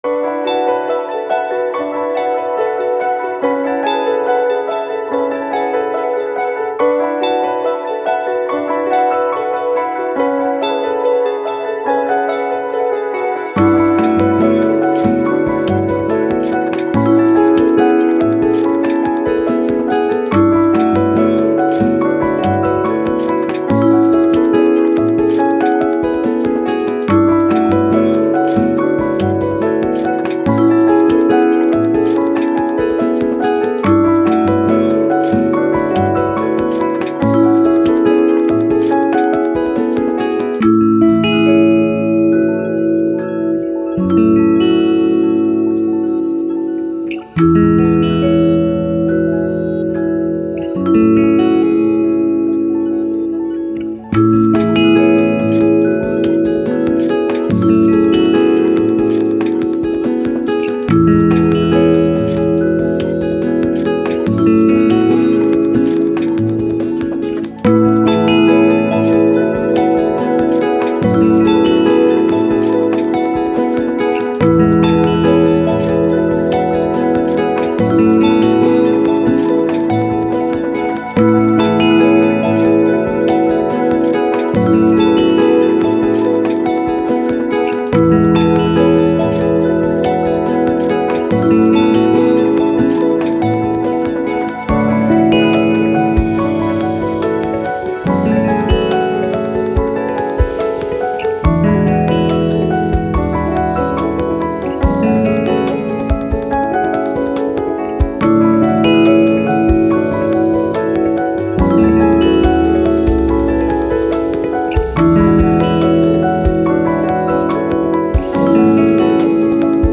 Ambient, Downtempo, Soundtrack, Journey, Hopeful